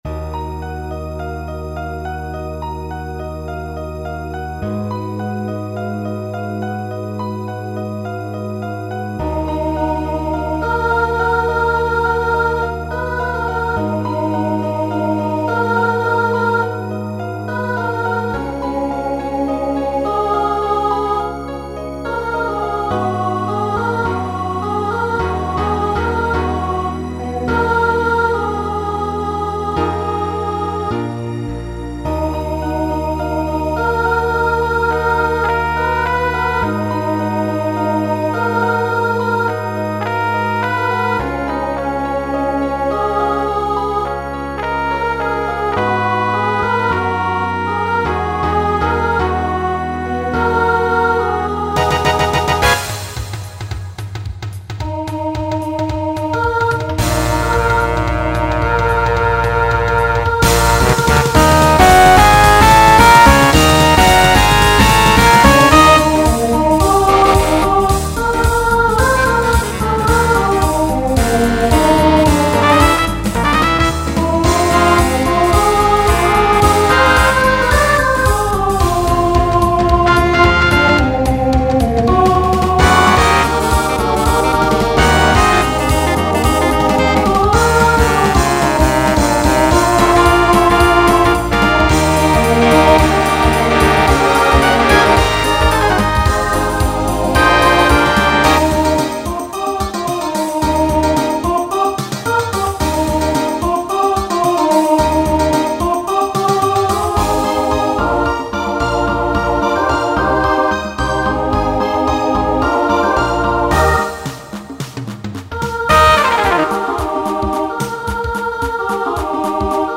Genre Broadway/Film Instrumental combo
Opener Voicing SSA